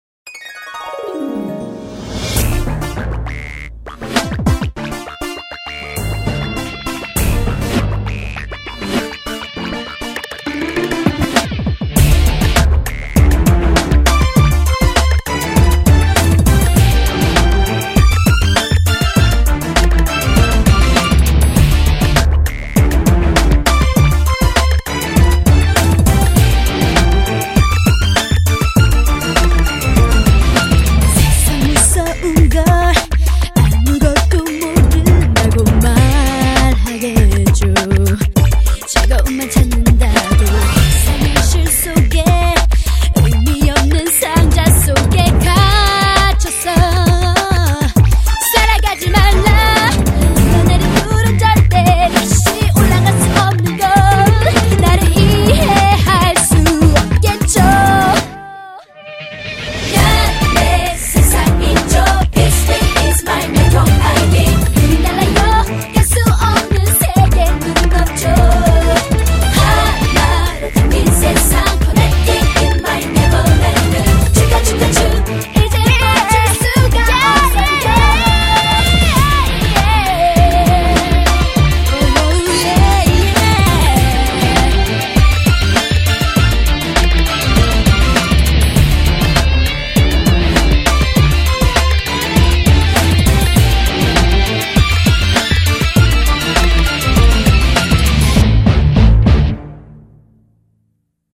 BPM99--1
Audio QualityPerfect (High Quality)